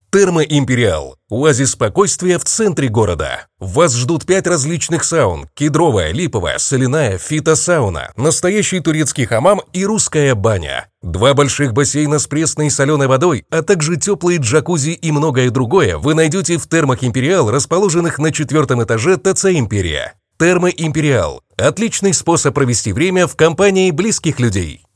Микрофон: Neuman TLM-102
Моя миссия - передавать слушателям уверенность, энергию и настроение.